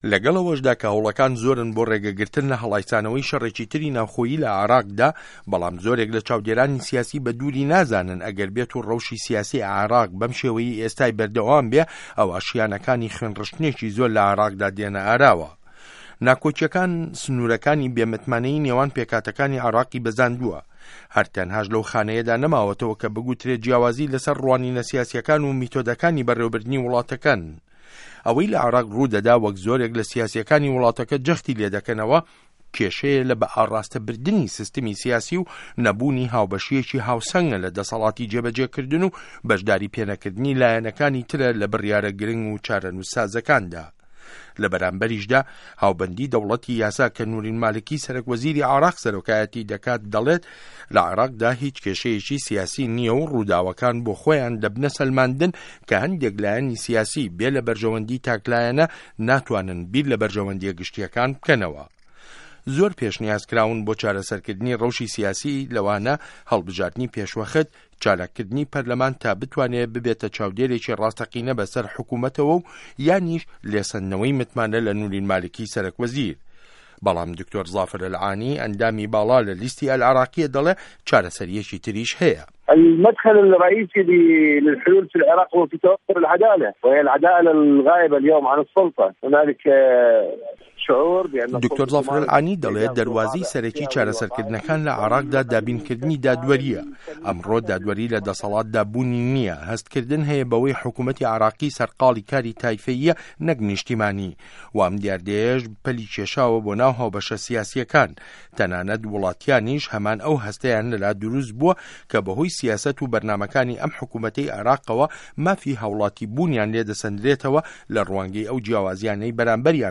ڕاپۆرت له‌سه‌ر بنچینه‌ی لێدوانه‌کانی دکتۆر زافر ئه‌لعانی